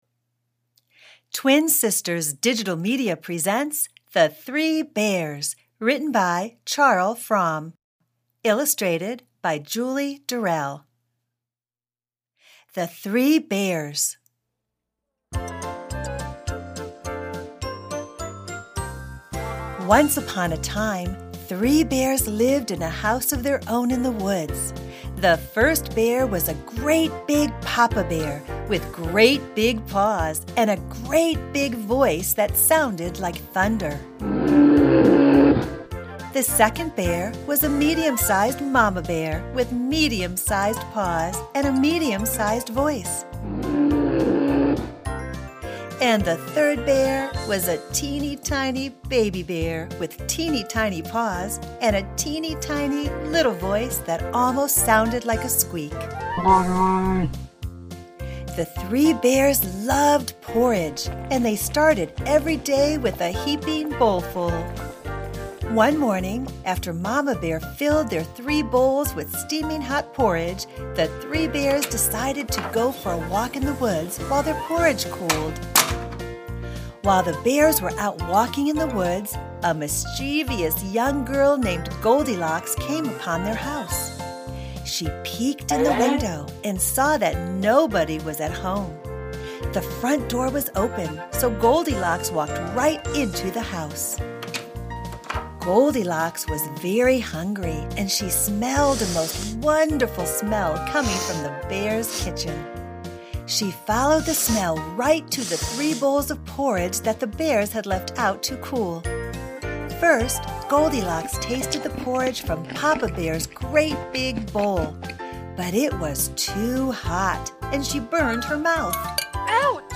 Reading The Three Bears